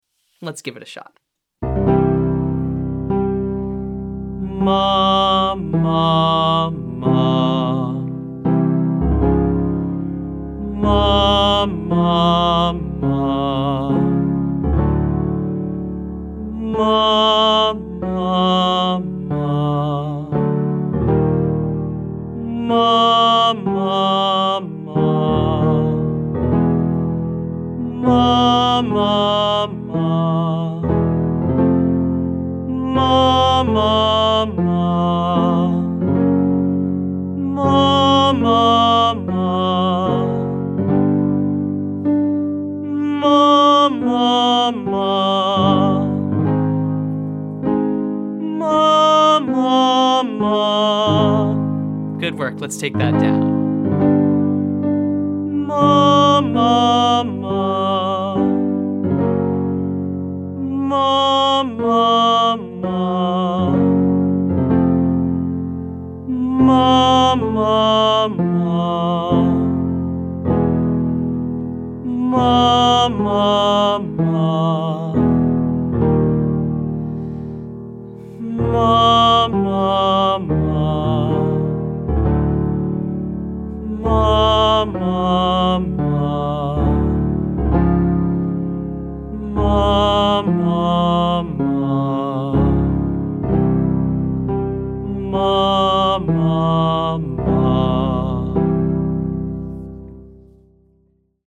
• Mah (5,3,1)